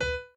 b_pianochord_v100l8o5b.ogg